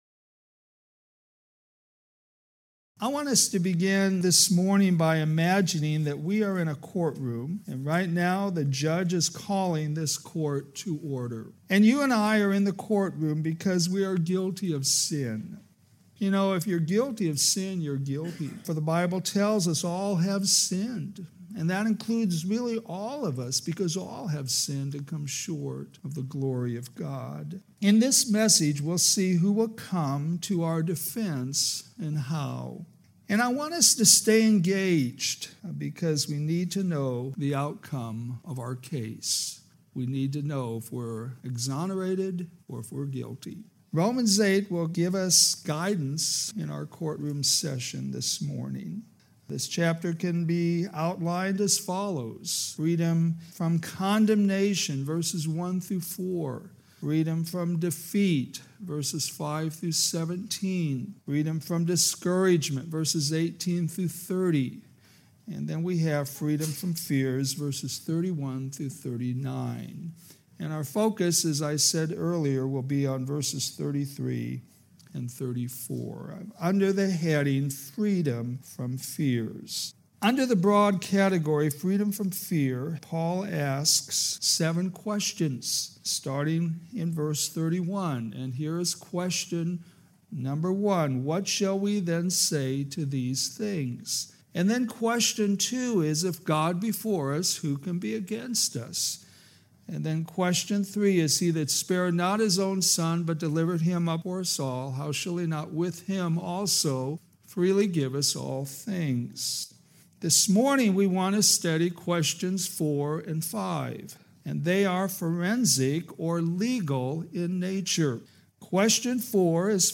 Sermons based on New Testament Scripture